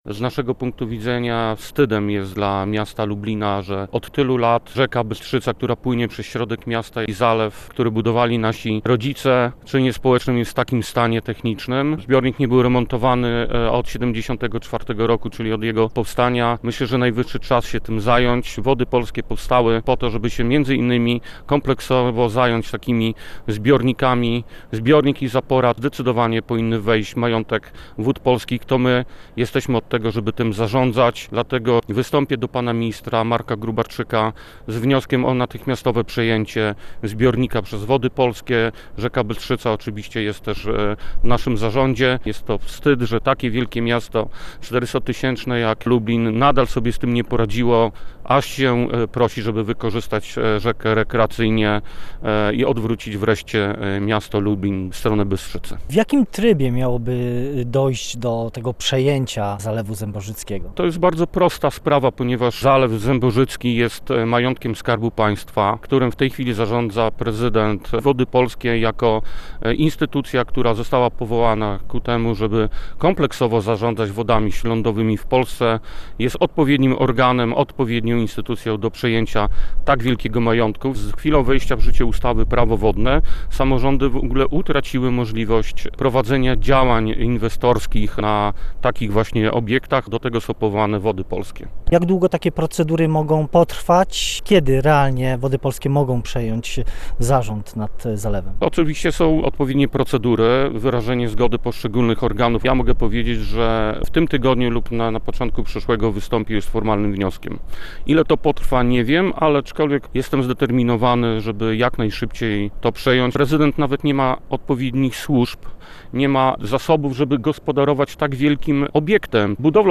W rozmowie z Polskim Radiem Lublin Przemysław Daca przekonuje, że miasto, które obecnie zarządza zalewem, nie ma możliwości, by przeprowadzać tam jakąkolwiek modernizację.